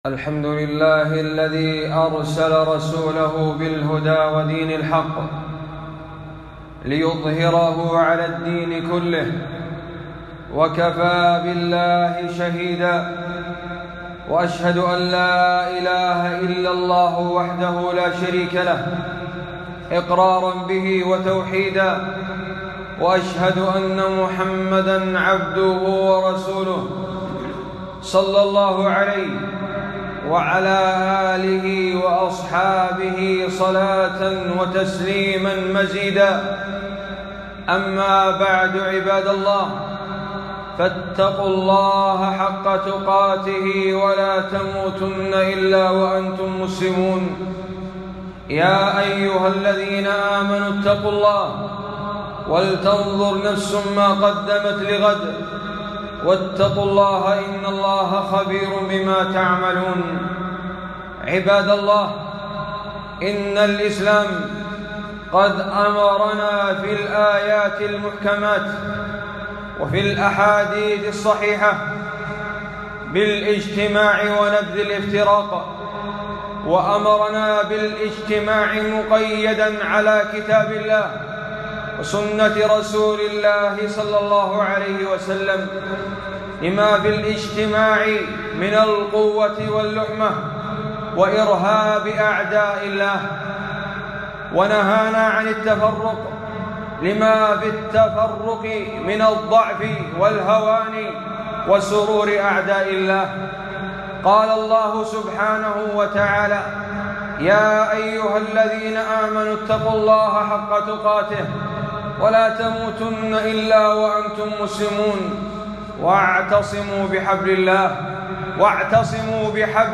خطبة - التبليغ (الأحباب) ضلالات ورد شبهات